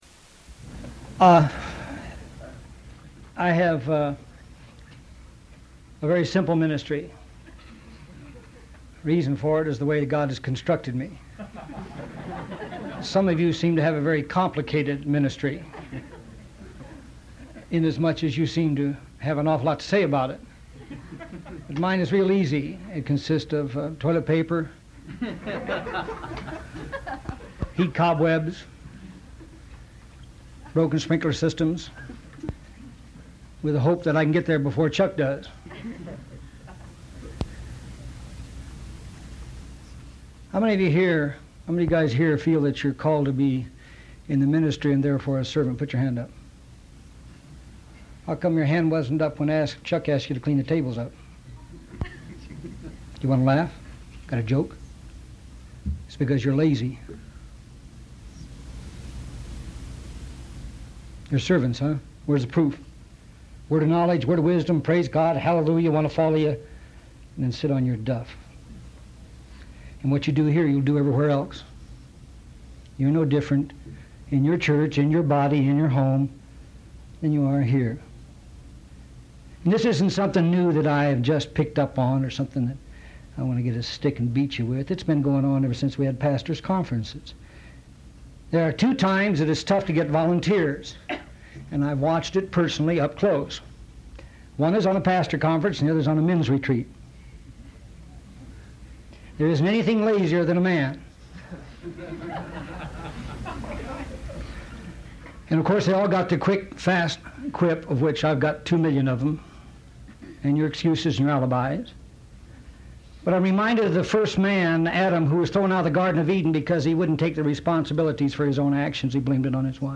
Ministering with Humility download sermon mp3 download sermon notes Welcome to Calvary Chapel Knoxville!